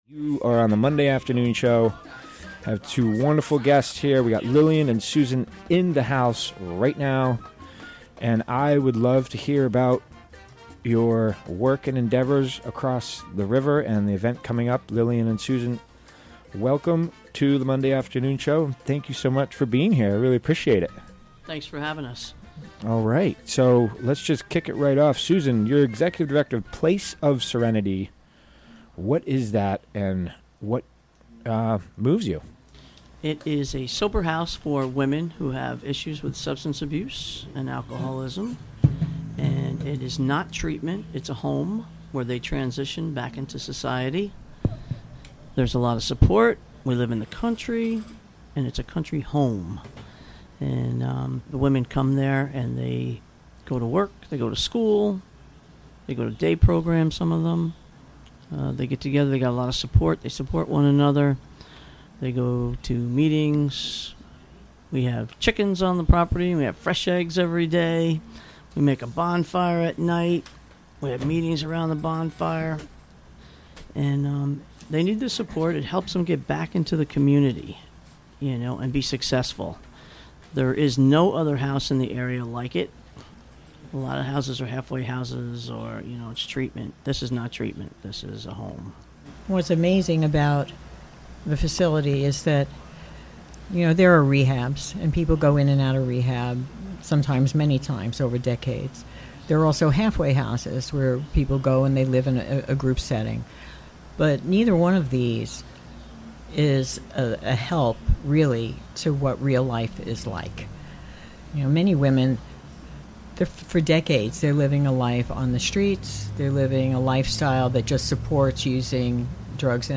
Recorded during the WGXC Afternoon Show Monday, September 18, 2017.